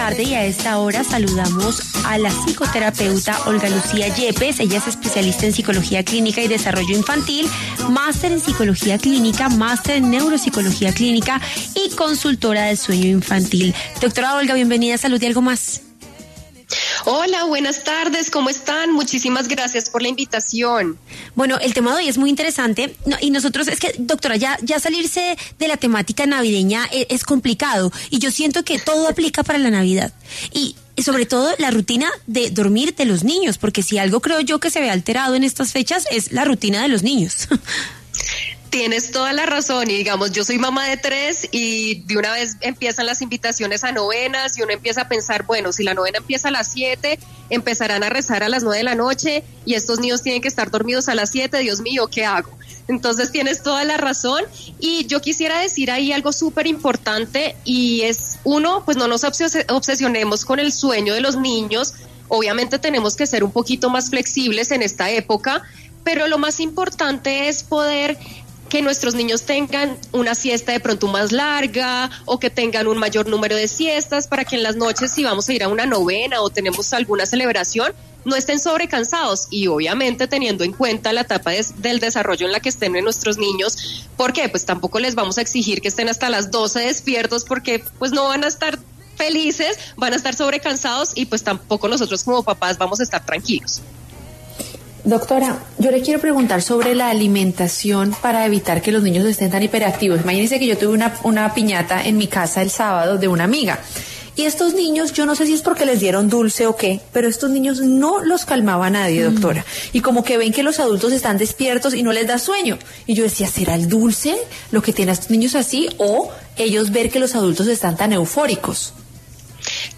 conversó con Salud y Algo Más sobre los cambios en las rutinas del sueño de los niños en estas fiestas de fin de año, dando recomendaciones.